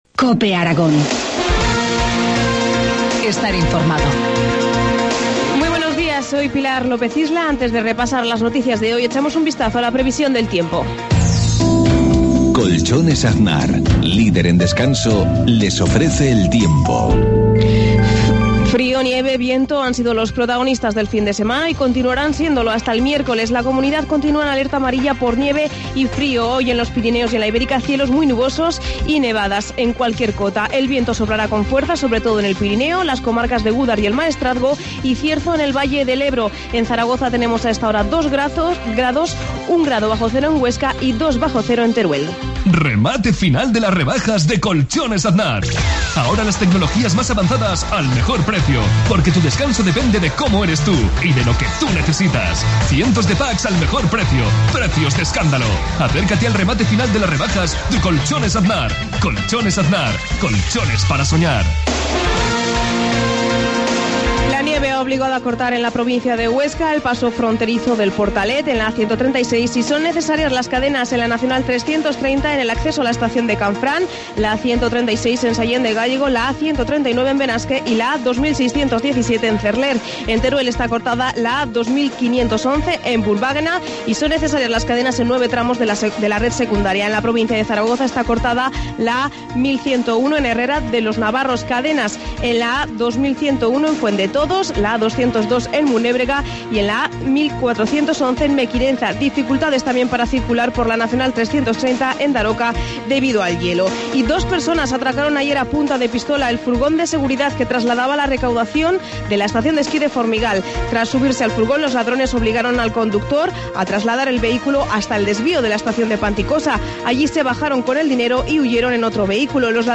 Informativo matinal, lunes 25 de febrero, 7.53 horas